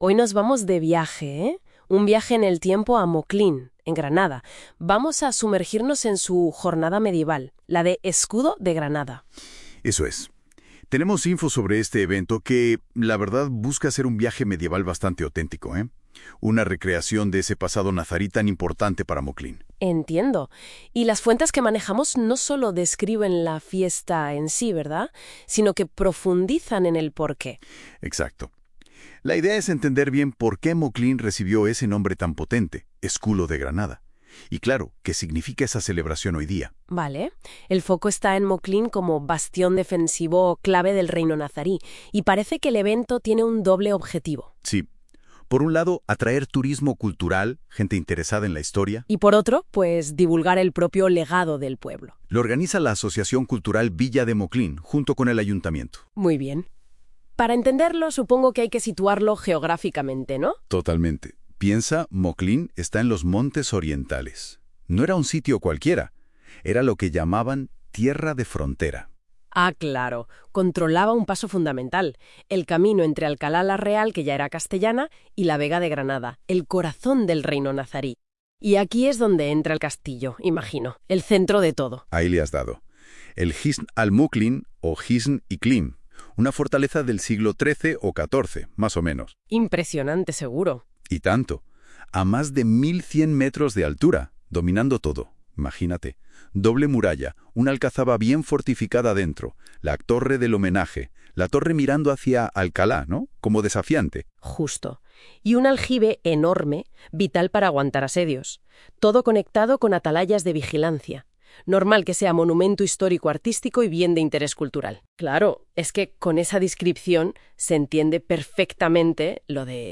Y por qué no! con ayuda de la inteligecia artificial, hemos creado un podcast de 5 minutos que cuenta de forma amena en qué consiste la Jornada Medieval Moclín Escudo de Granada
podcast-moclin-escudo-de-granada-2025-ia.mp3